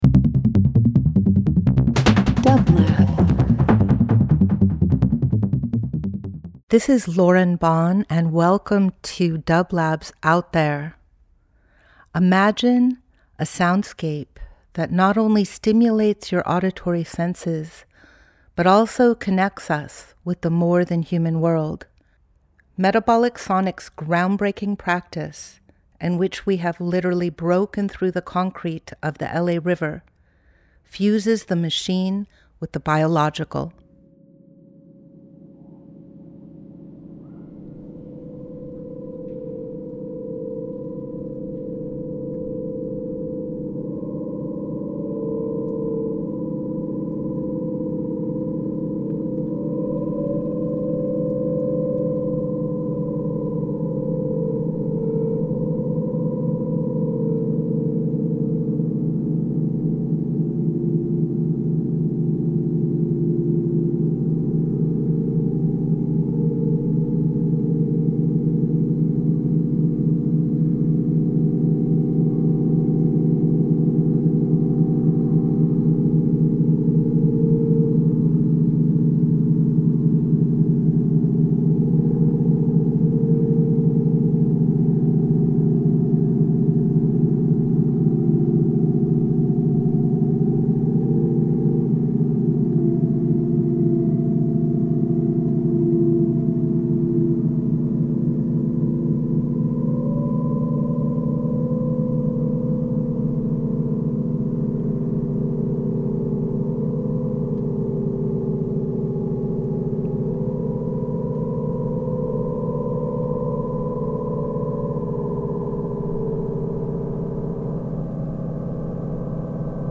Each week we present field recordings that will transport you through the power of sound. Metabolic Sonics specializes in exploring the captivating sound waves and vibrations of the web of life.
Each week we will bring field recordings of Metabolic’s current project Bending the River, and archival material from past ventures. Bending the River is an adaptive reuse of the LA River infrastructure that reimagines the relationship between Los Angeles and the river that brought it into existence.